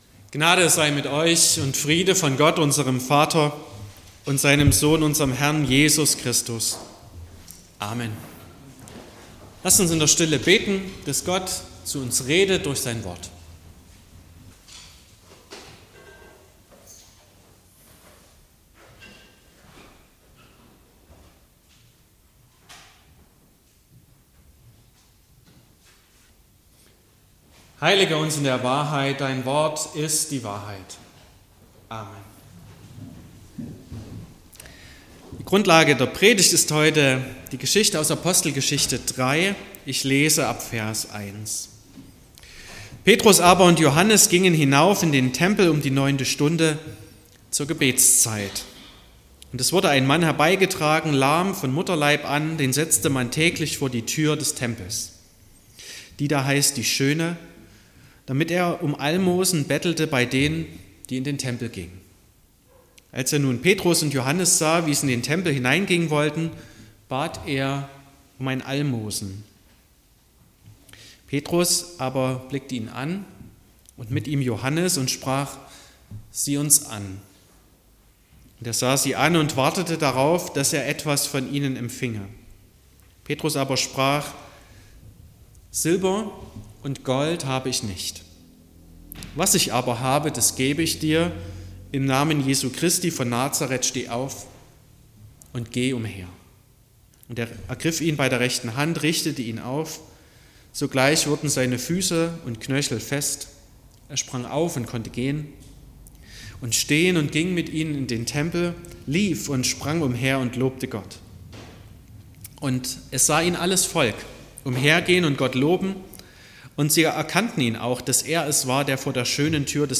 07.09.2025 – Gottesdienst
Predigt und Aufzeichnungen